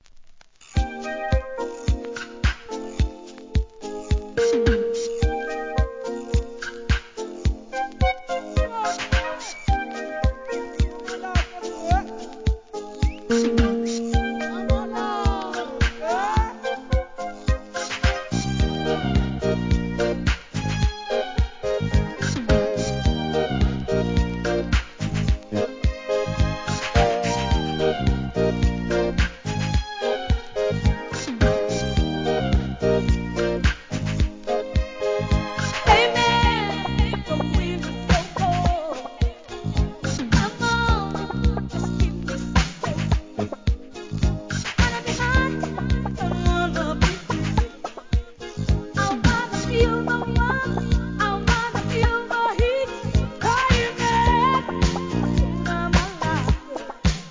¥ 880 税込 関連カテゴリ SOUL/FUNK/etc...